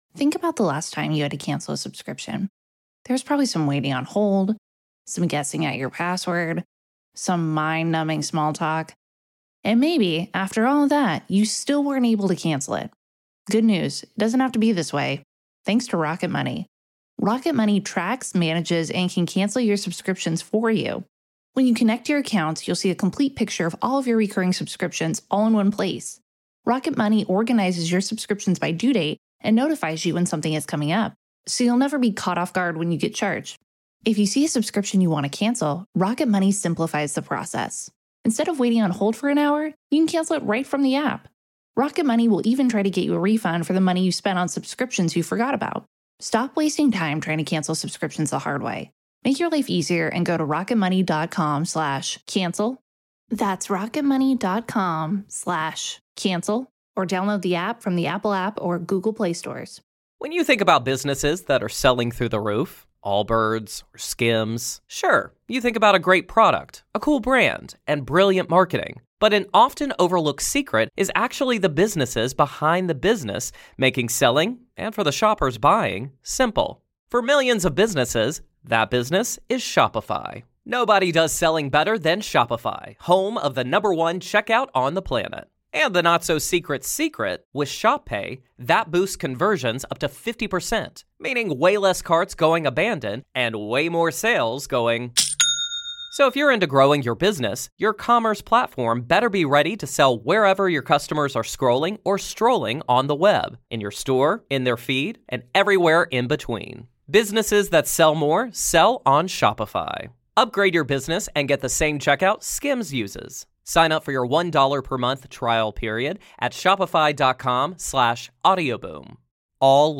It's time to lock in, stay focused, and discipline yourself. Powerful Motivational Speech